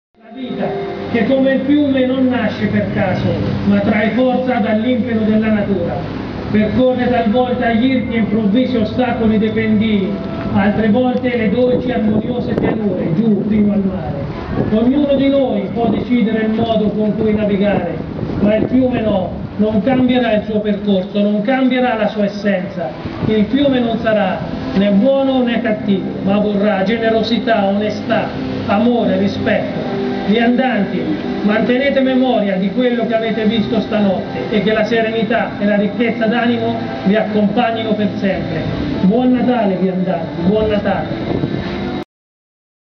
Chiudiamo però ricordando i due cantori che nel (ormai) lontano 2010 allietavano l'uscita dei viandanti dal Presepe di Petrignano di Assisi
qui per ascoltare il primo cantore e qui per ascoltare il secondo cantore.